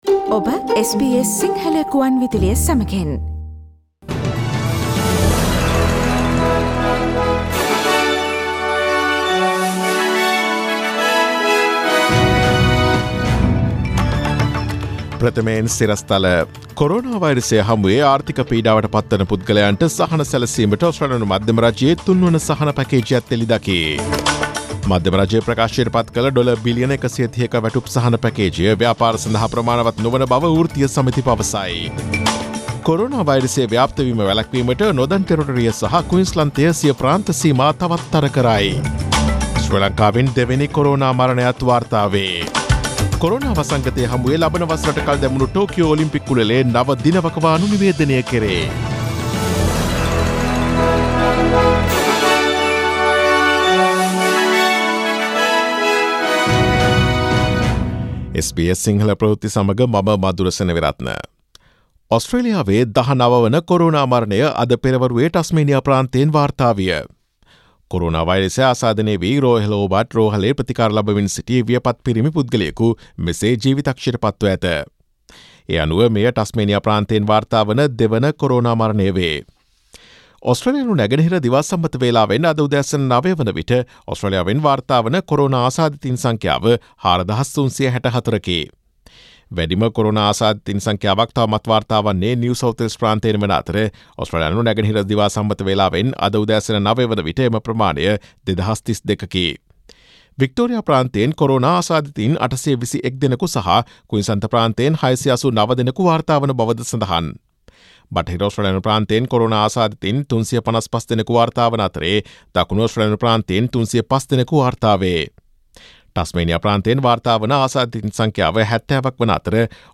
Daily News bulletin of SBS Sinhala Service: Tuesday 31 March 2020